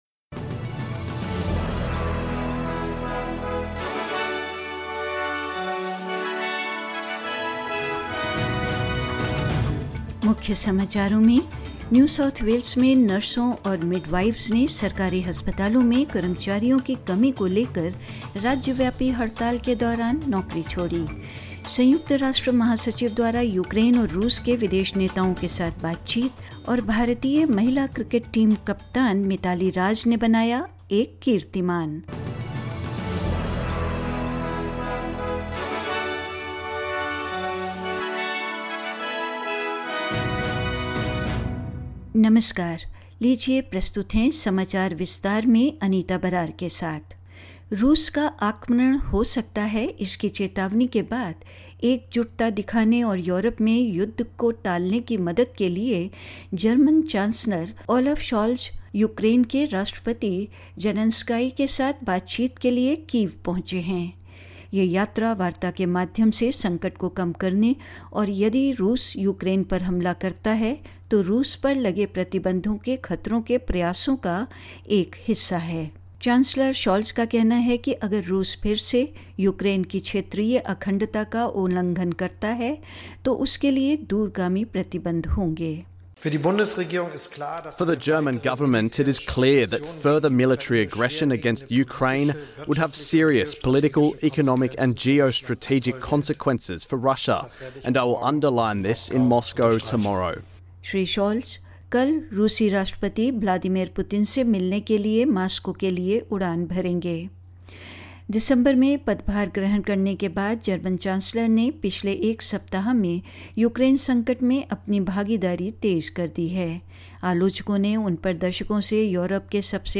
In this latest SBS Hindi bulletin: Nurses and midwives across New South Wales walk off the job during a state wide strike over staff shortages in public hospitals; The UN Secretary general hold talks with Ukraine and Russia's foreign leaders; Indian cricketer Mithali Raj becomes the first ODI captain to score 5000 plus runs in women’s cricket and more news.